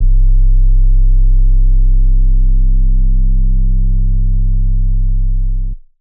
808 (London Slide Sub).wav